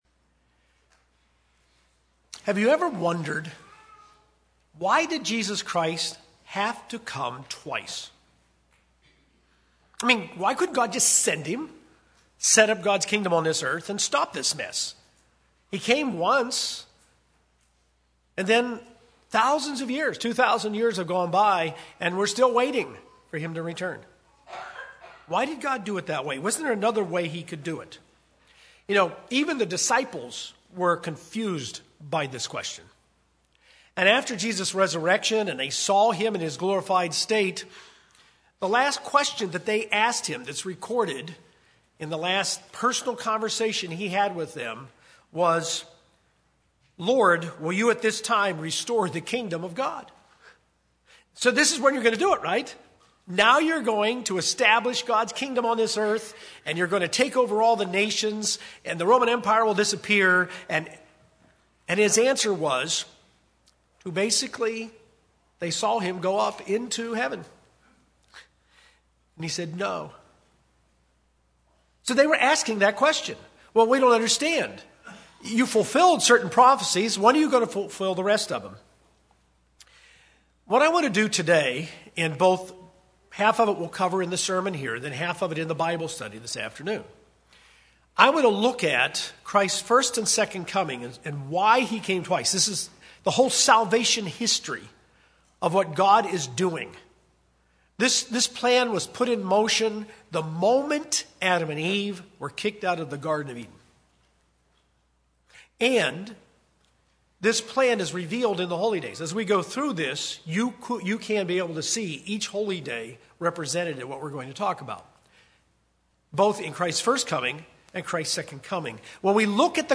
Jesus' first coming fulfilled many essential requirements that set the stage for His second coming. This sermon quickly overviews seven reasons for His first coming.